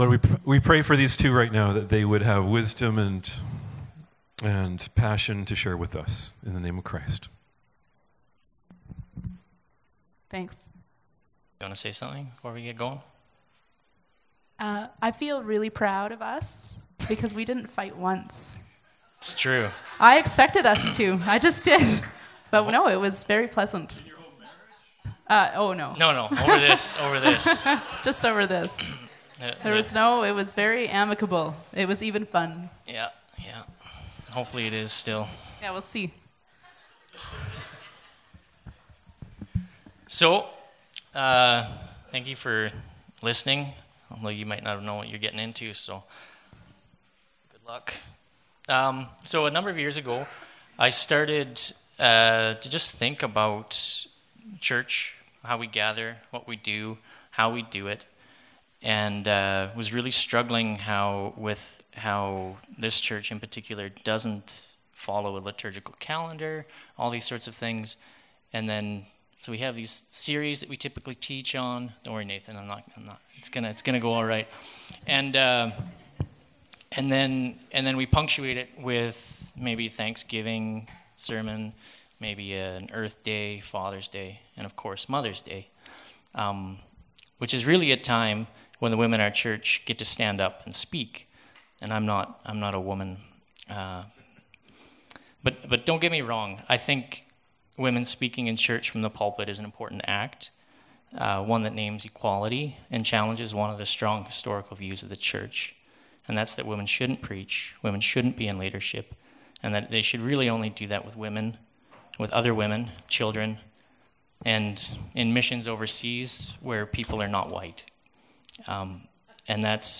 Service Type: Downstairs Gathering